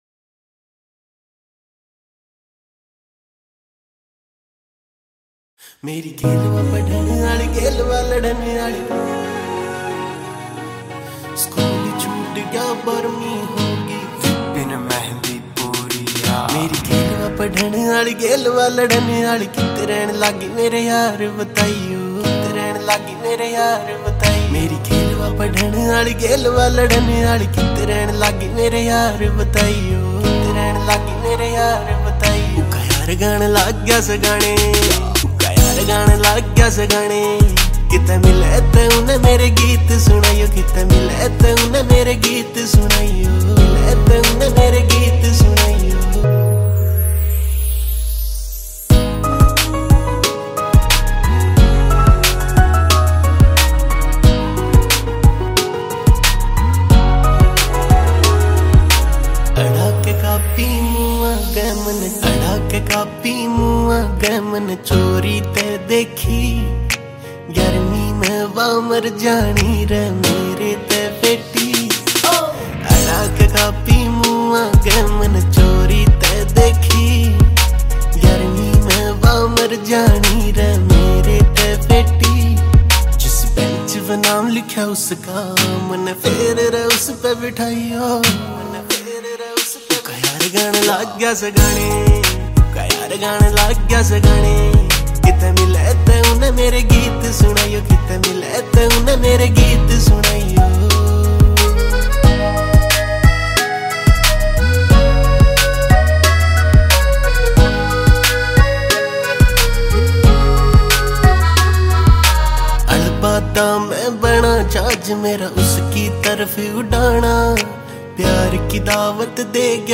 Haryanvi Single Tracks